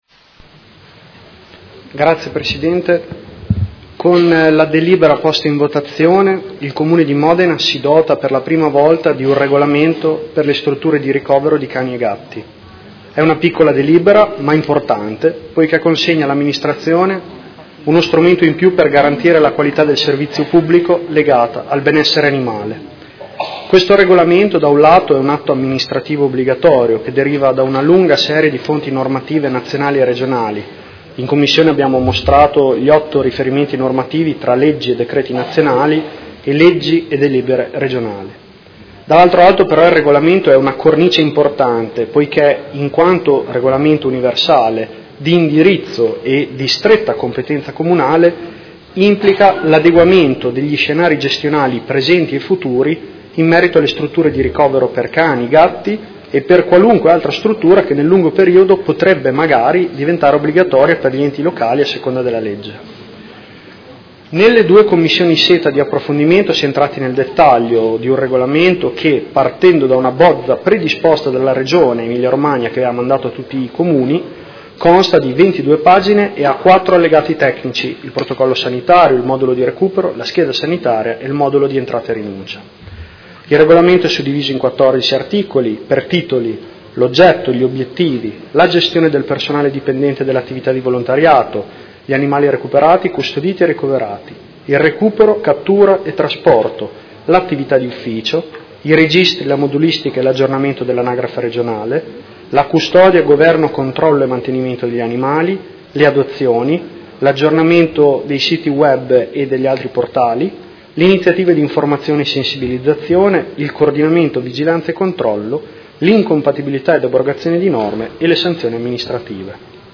Seduta del 14/12/2017 Delibera. Regolamento Comunale strutture di ricovero per cani e gatti ai sensi della L.R. n. 27/2000